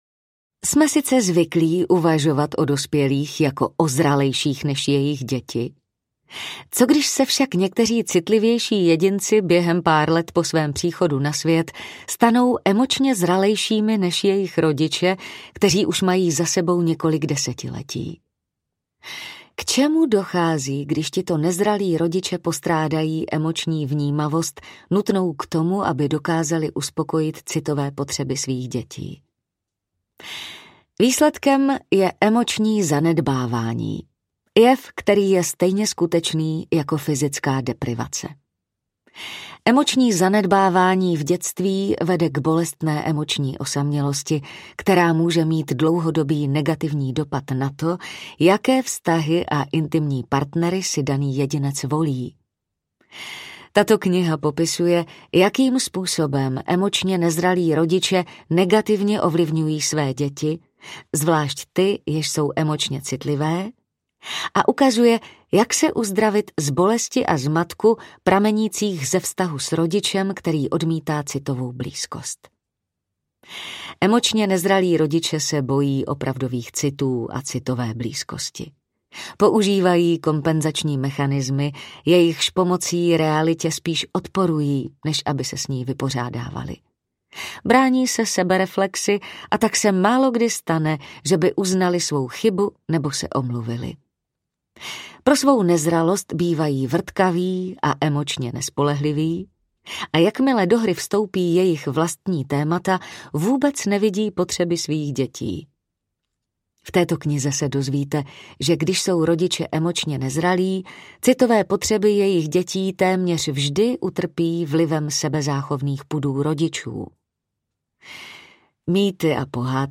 Audiokniha Dospělé děti emočně nezralých rodičů od Lindsay Gibson.